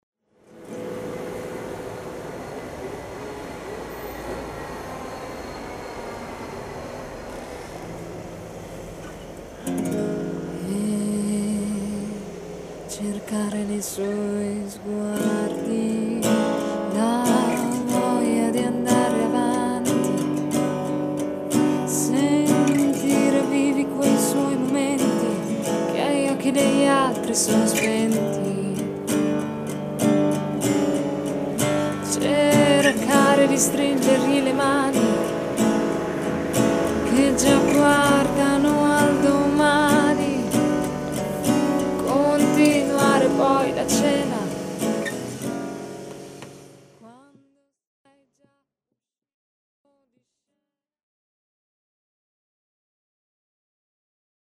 con chiare contaminazioni indie-rock e jazz.